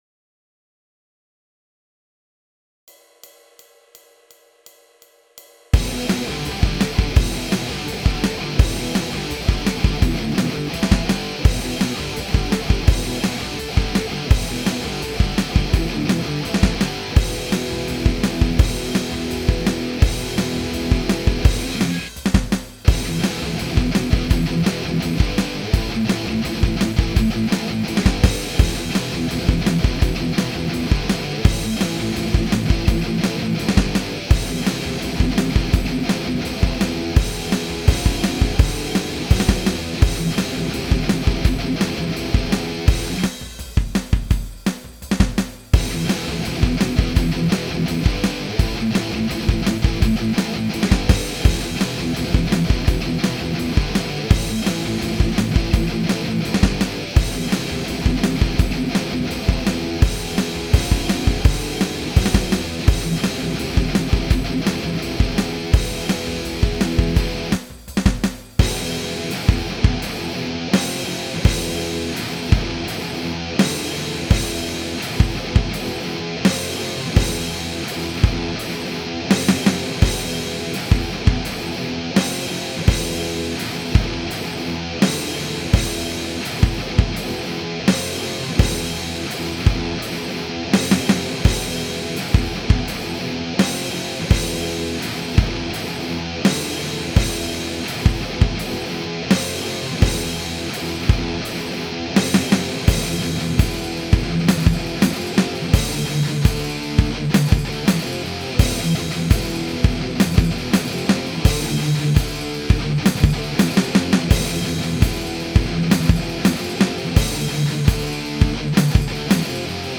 단순한 리프만 생산하던 나와 달리 생각보다 변화무쌍한 형님의 기타리프에 감동했다.